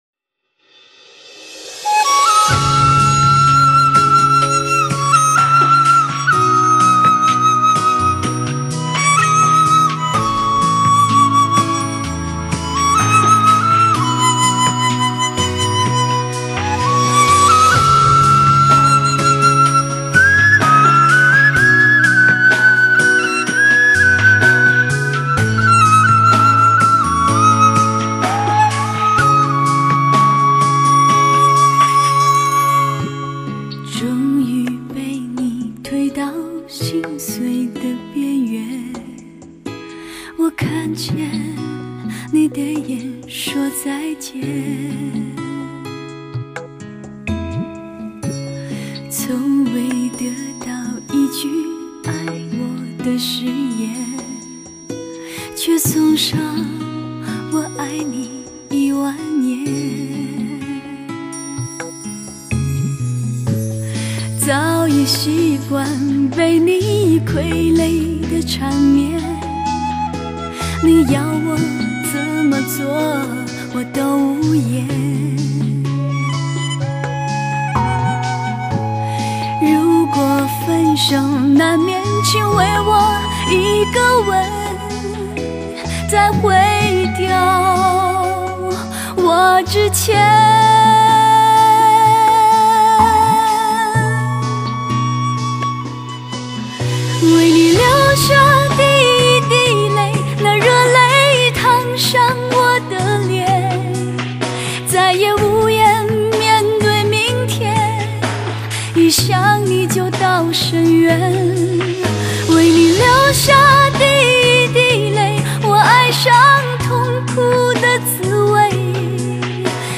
音乐的立体感、层次感和开阔的空间感营造出不一般的驾乘感觉。